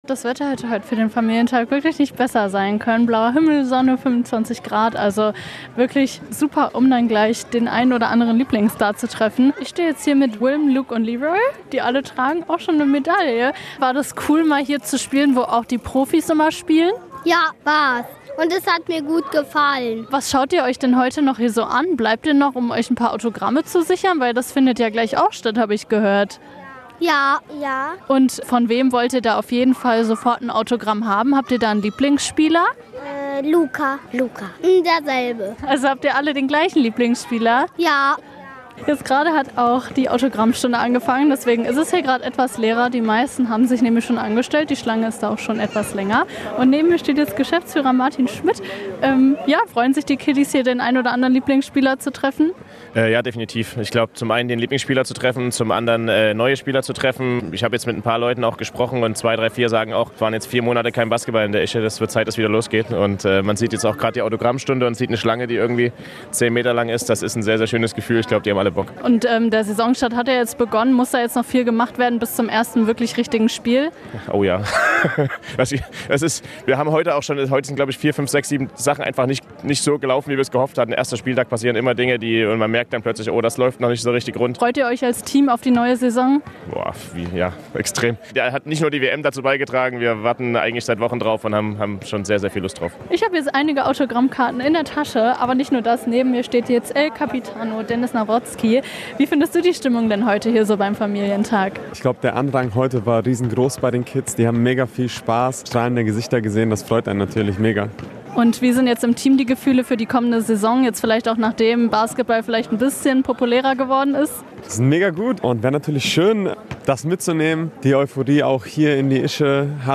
Grundschul-Basketball-Turnier, Autogrammstunde, Korbjagd der Profis... Gestern war in der Ischelandhalle ein ziemlich buntes Treiben.